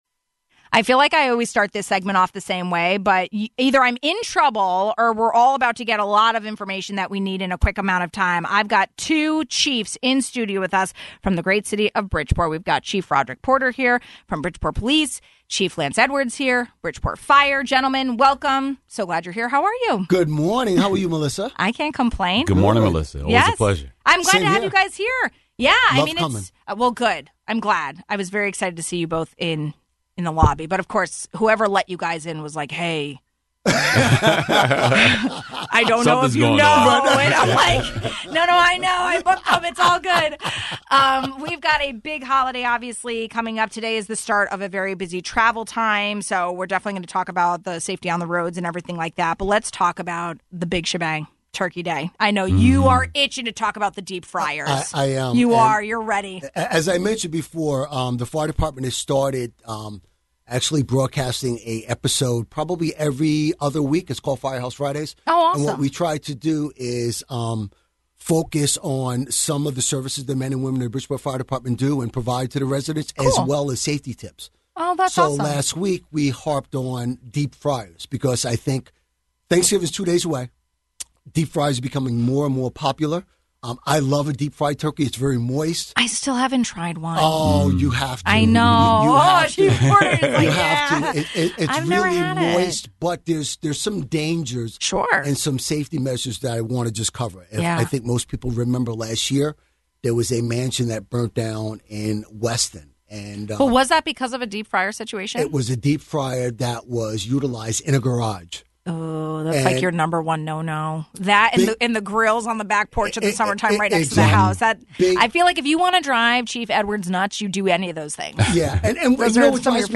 We spoke with Bridgeport Police Chief Roderick Porter and Bridgeport Fire Chief Lance Edwards about porch pirates, deep fryers and more ahead of the Thanksgiving holiday week.